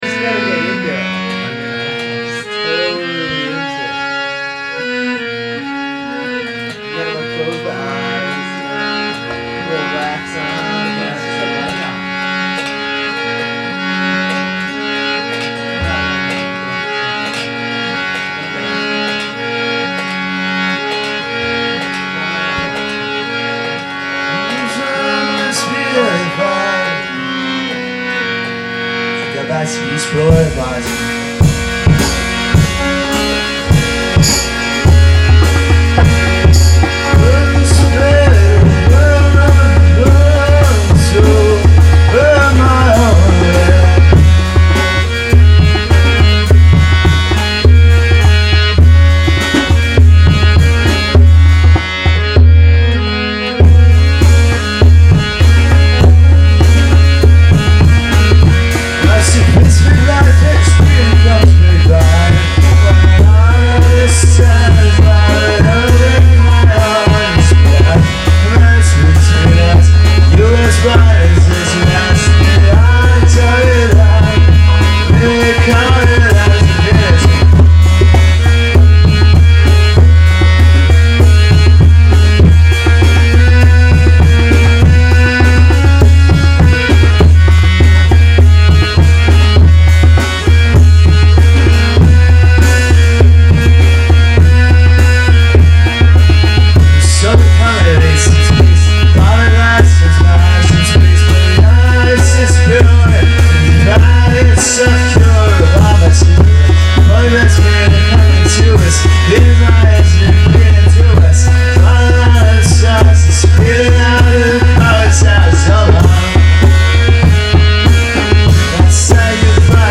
here are some demos.